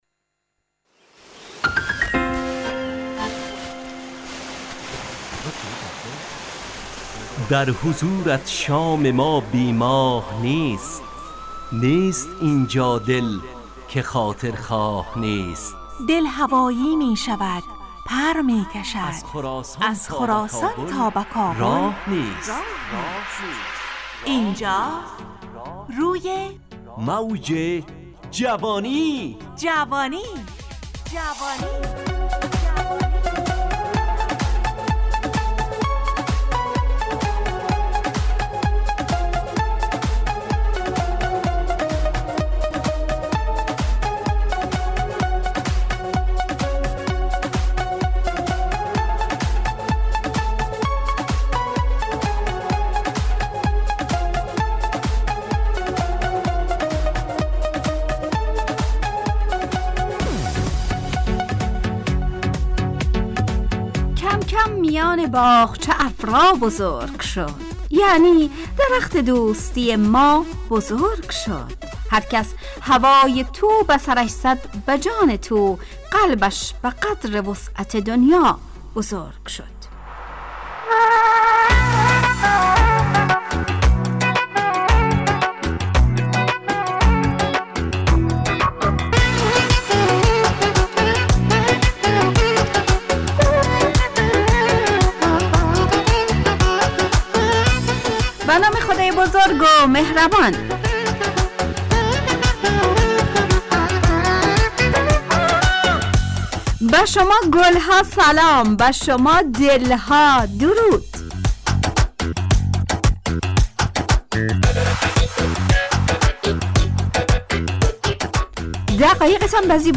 برنامه شادو عصرانه رادیودری
همراه با ترانه و موسیقی مدت برنامه 70 دقیقه . بحث محوری این هفته (دیدن و شنیدن) تهیه کننده